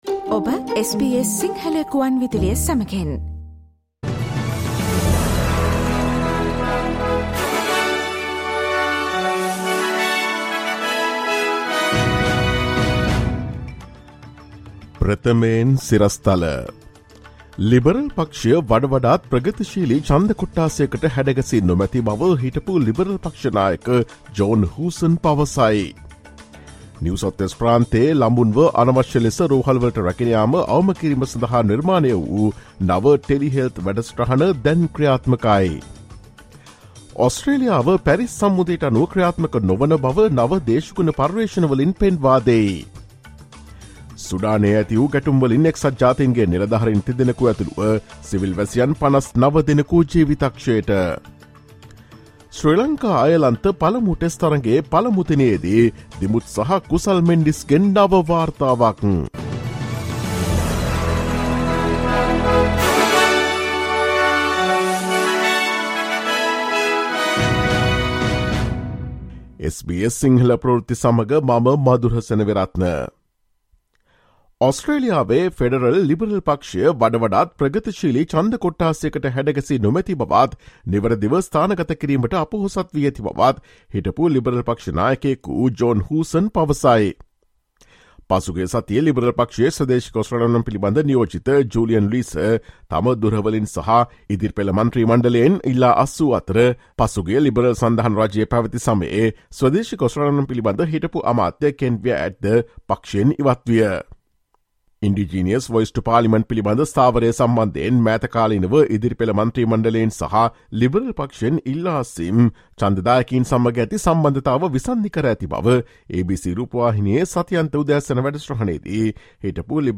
ඔස්ට්‍රේලියාවේ නවතම පුවත් මෙන්ම විදෙස් පුවත් සහ ක්‍රීඩා පුවත් රැගත් SBS සිංහල සේවයේ 2023 අප්‍රේල් 17 වන දා සඳුදා වැඩසටහනේ ප්‍රවෘත්ති ප්‍රකාශයට සවන් දෙන්න.